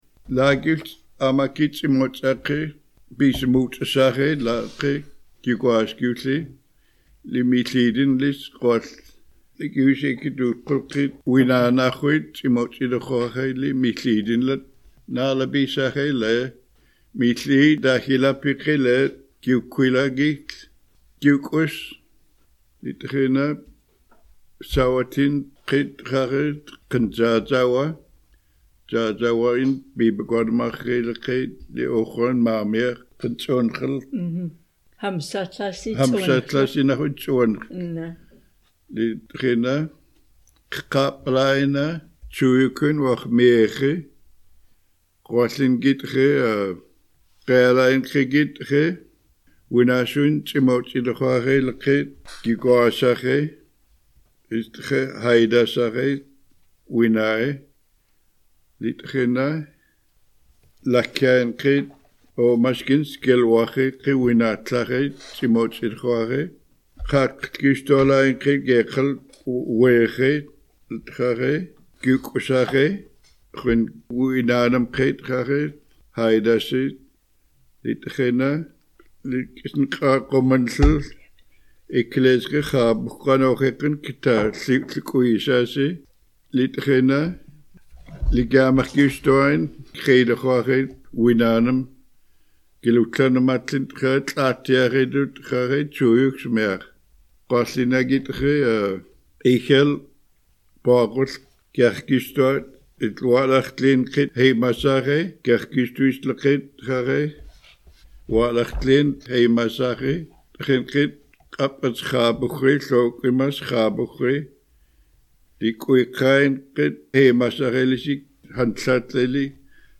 Canoe_Haida_story.mp3